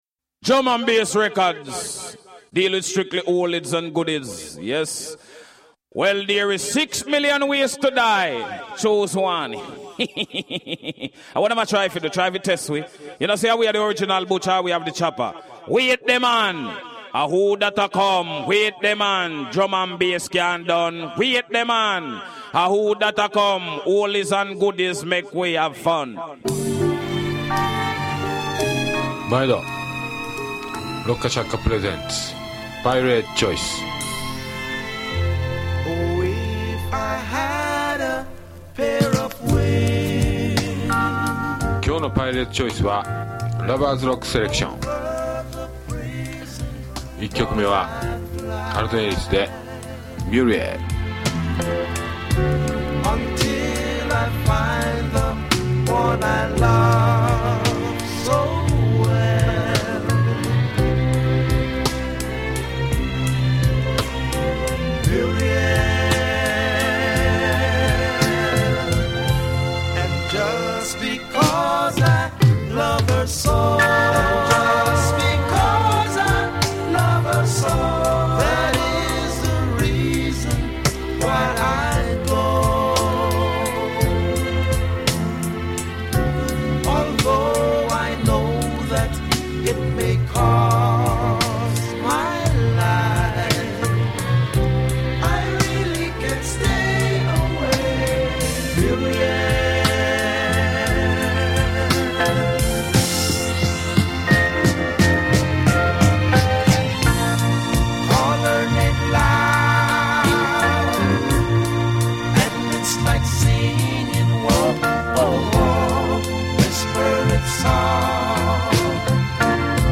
＊試聴はダイジェストです。